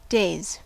Ääntäminen
Ääntäminen US Tuntematon aksentti: IPA : /ˈdeɪz/ Haettu sana löytyi näillä lähdekielillä: englanti Käännös Substantiivit 1. giorni {m} 2. epoca {f} Days on sanan day monikko.